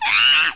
Обезьянка вскрикнула неожиданно